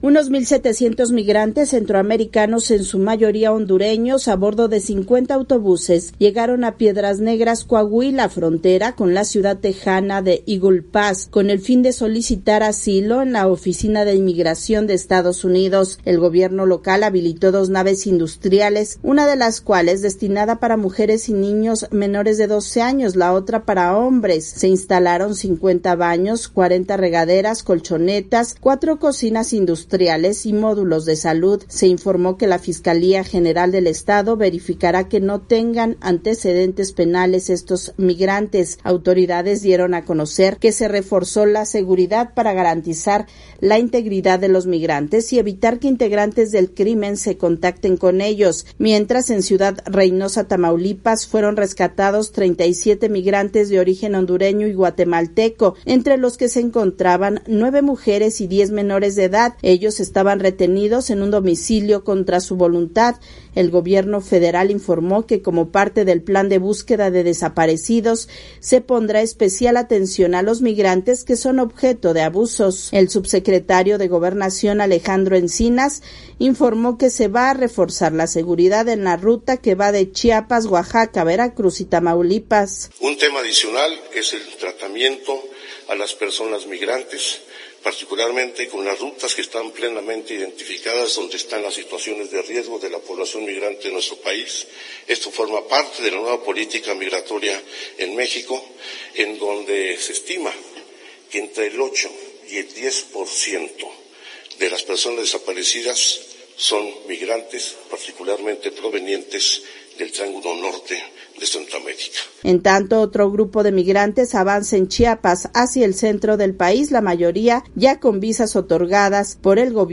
VOA: Informe desde México